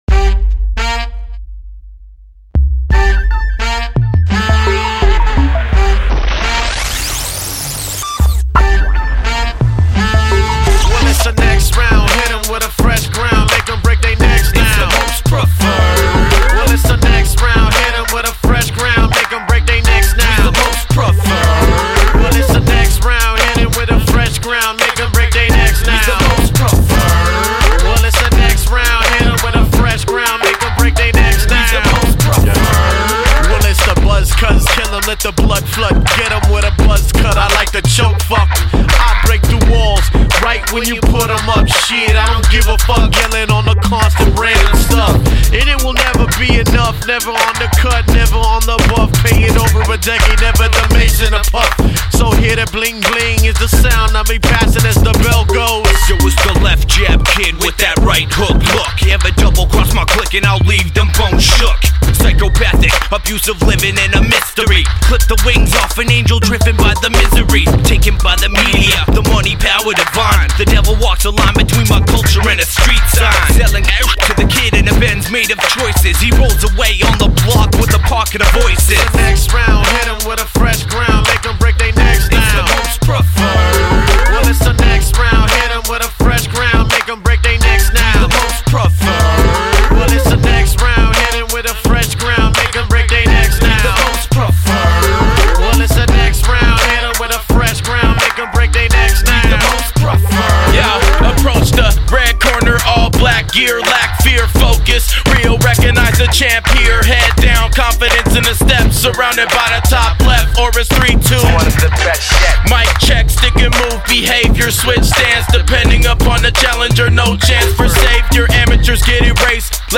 hip hop collective